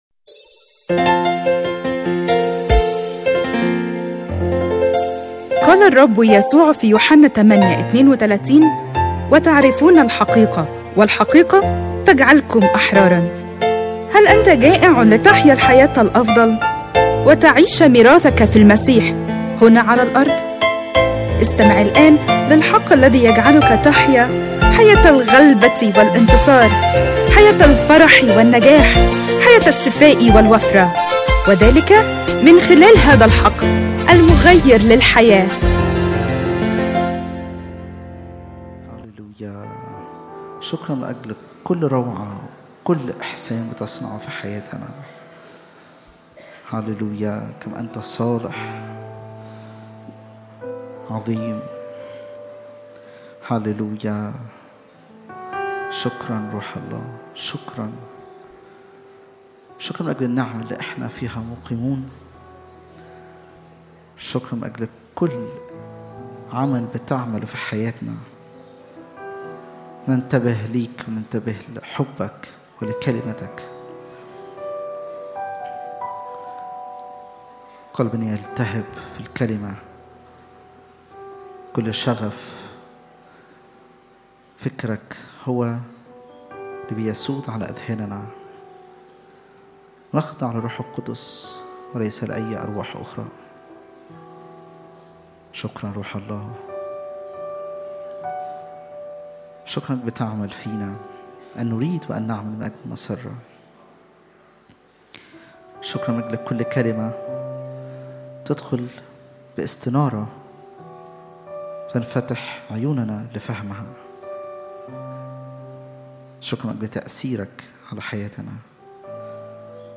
اجتماع الثلاثاء 25/11/2025